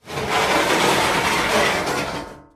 slide.mp3